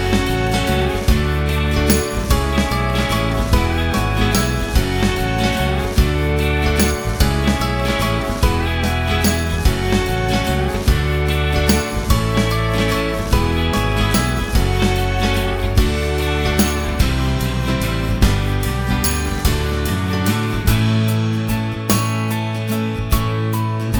Pop (1970s)